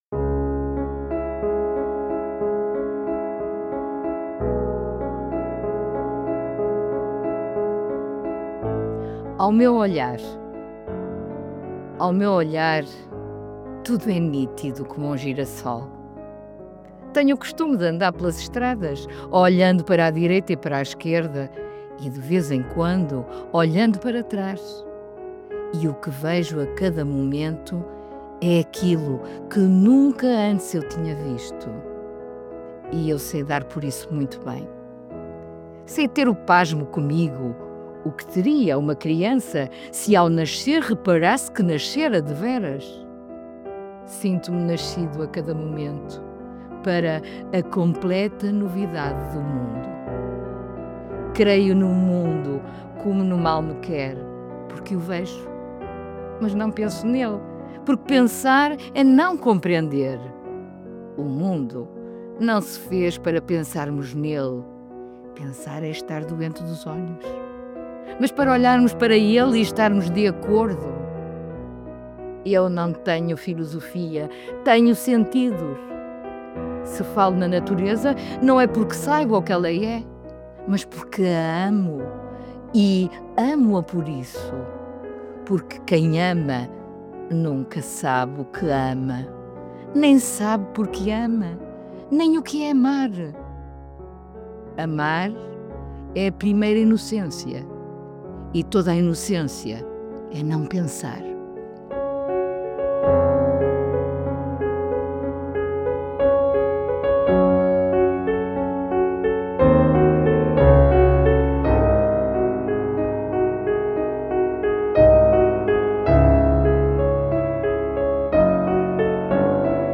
Música: Moonlight Sonata – Op. 27, Nr. 2 – Concert Grand Version